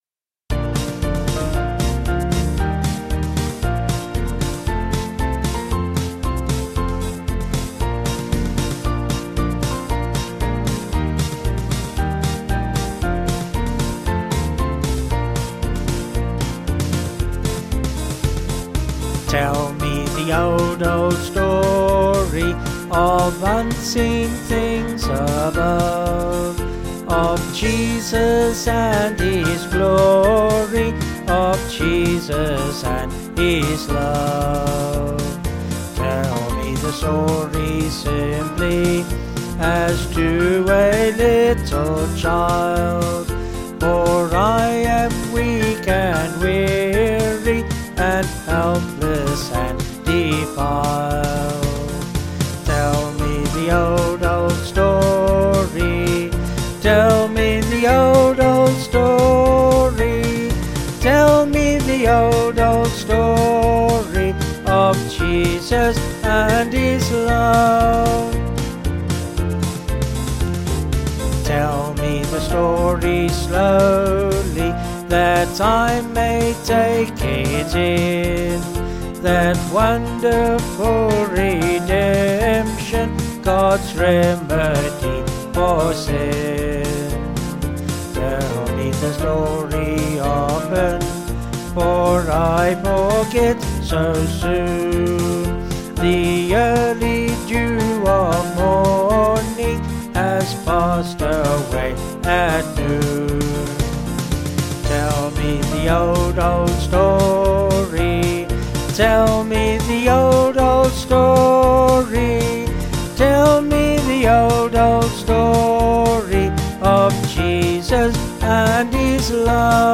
Vocals and Band   264.4kb Sung Lyrics